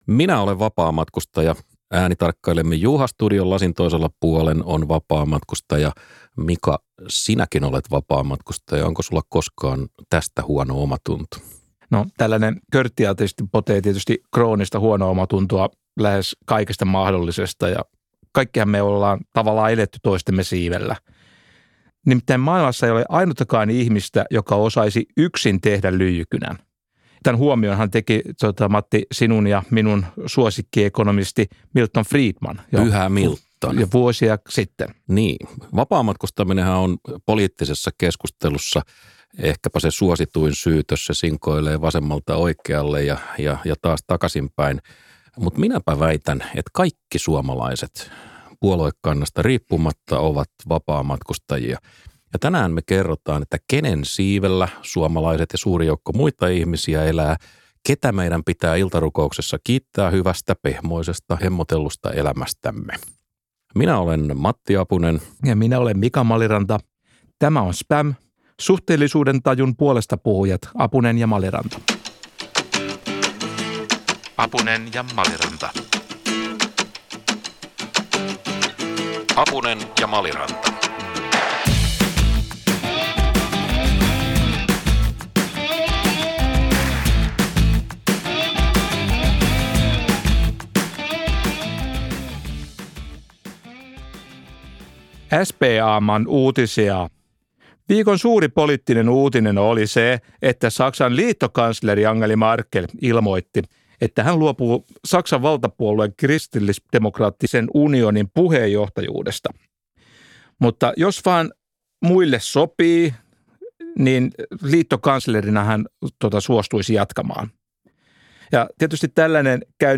keskustelevat jaksossa siitä, millaista vapaamatkustaminen on koko maailman mittakaavassa. Ja luvassa on Pohjoismaa-aiheinen Acemoglu-hetki.